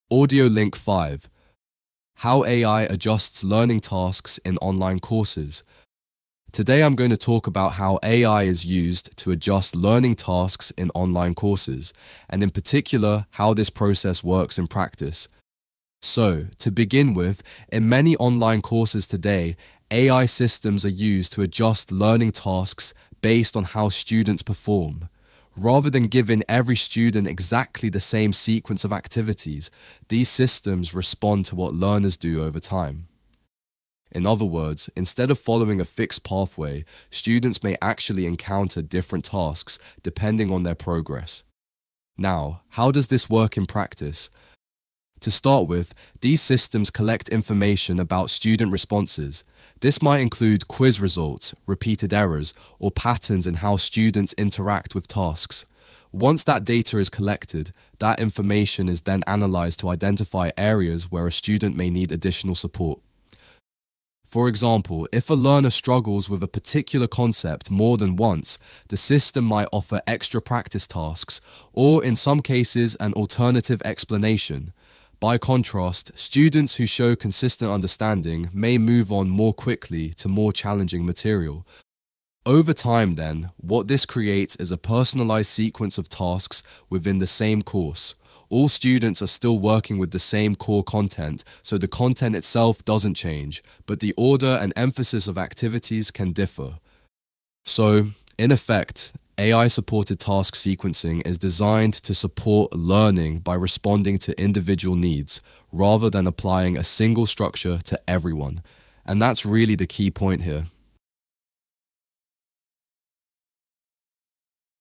Lecture 3: How AI adjusts learning tasks in online courses